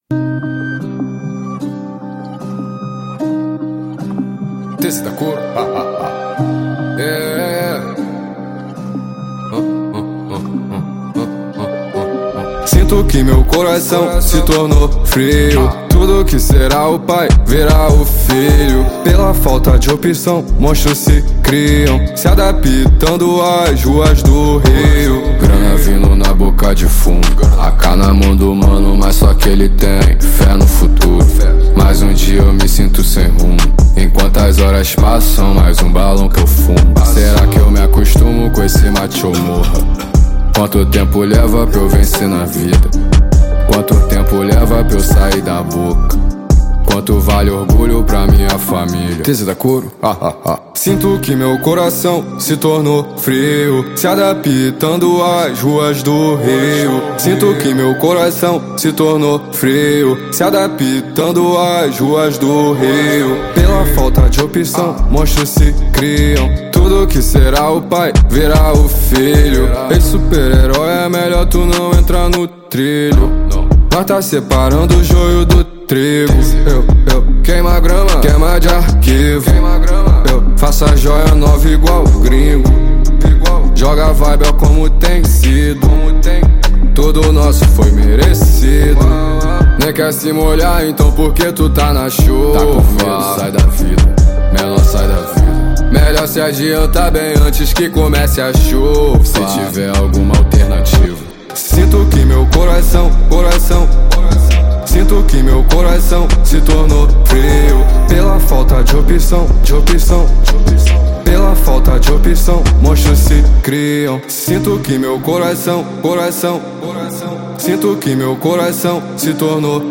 2025-03-19 01:43:58 Gênero: Trap Views